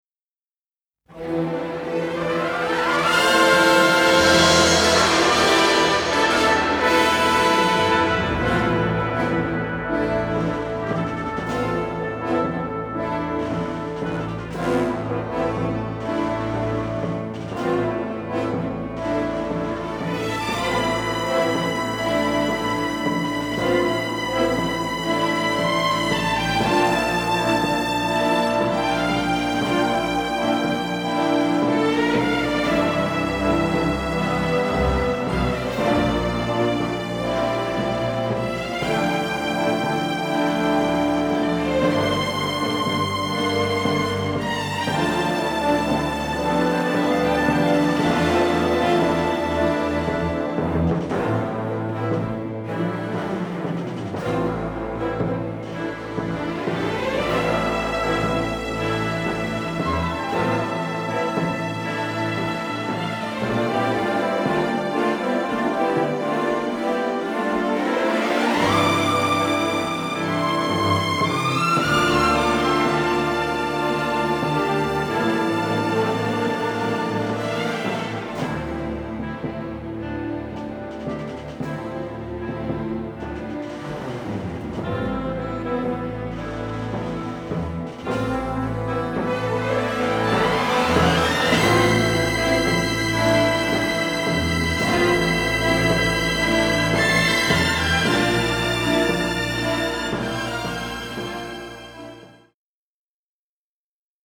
striking western score
unedited 2-track stereo masters